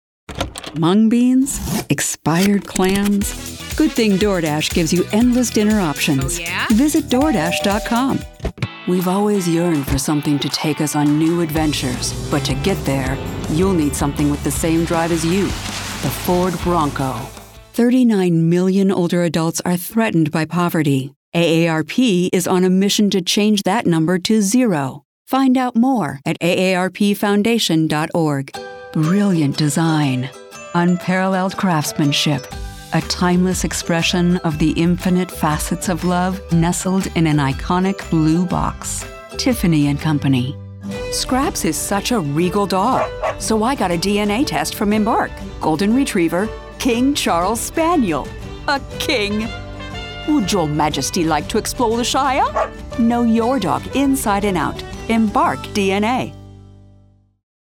2024 Commercial Demo
British, American Southern
Middle Aged
Are you looking for a warm, conversational tone that draws your listener in?